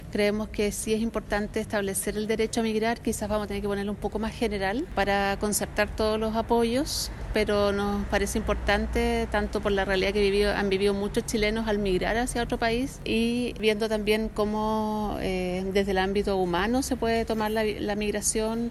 Lorena Céspedes, también coordinadora, acusó que será necesario generar cambios en la propuesta.